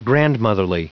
Prononciation du mot grandmotherly en anglais (fichier audio)